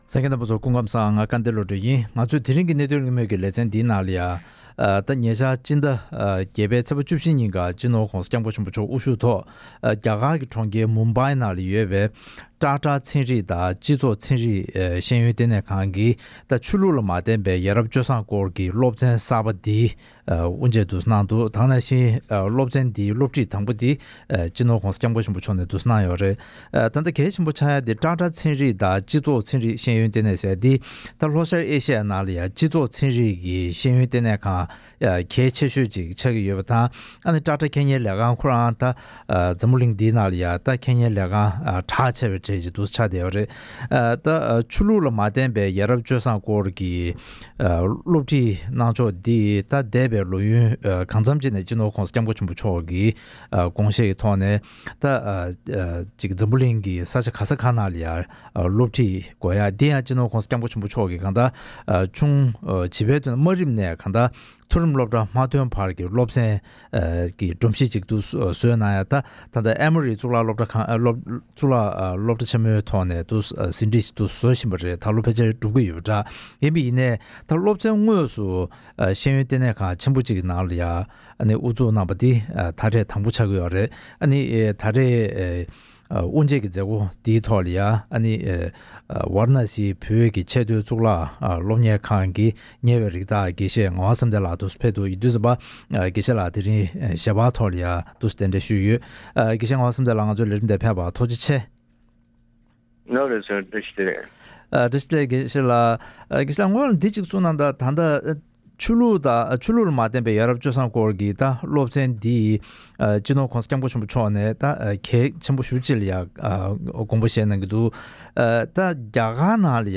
༄༅། །ཐེངས་འདིའི་གནད་དོན་གླེང་མོལ་གྱི་ལེ་ཚན་ནང་དུ།